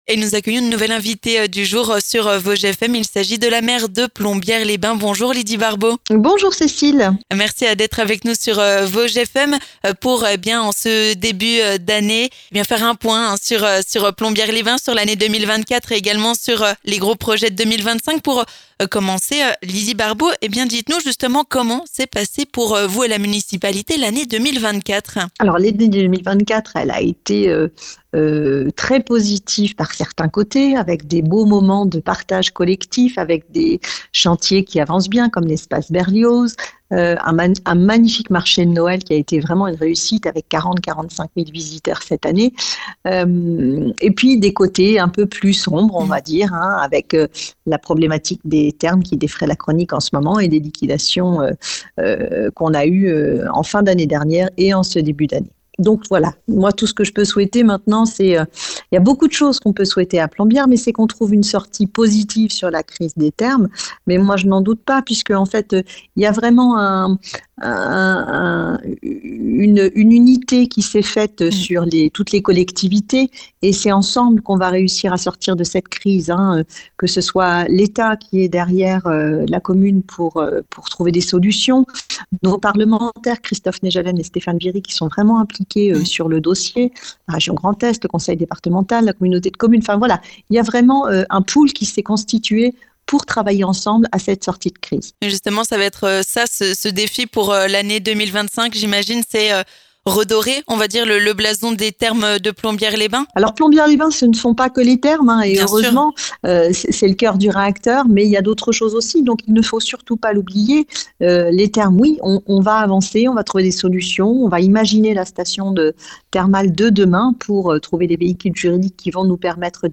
A cette occasion, Lydie Barbaux, maire de Plombières-les-Bains est notre invitée sur Vosges FM.